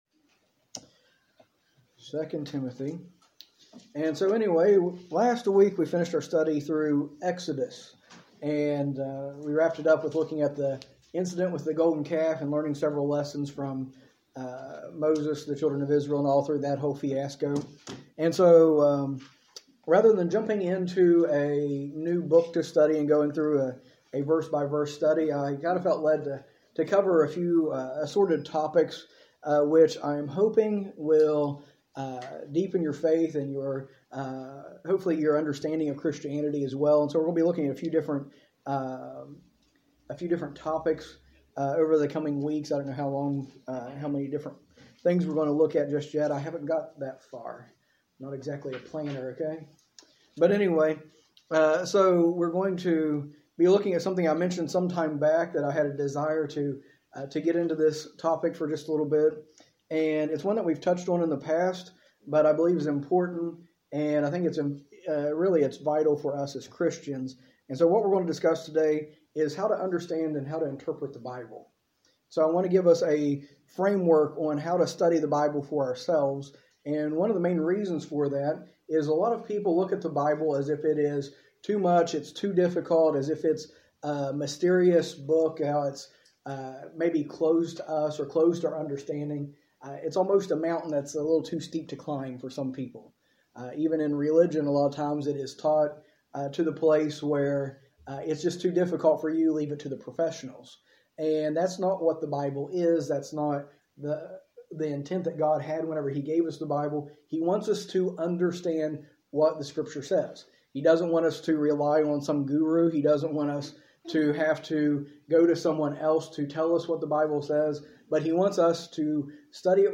A message from the series "Practical Christianity."
In this series, taught during our adult Sunday School, we explore basic principles and teachings of Christianity that will help us live our faith in everyday life.